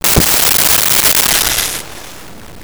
Snake Strike 01
Snake Strike 01.wav